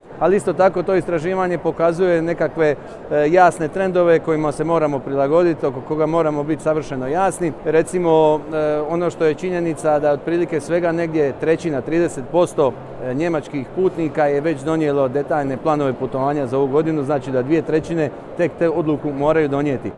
Izjavio je u Berlinu ministar turizma Tonči Glavina te nastavio govoreći o adutima Hrvatske.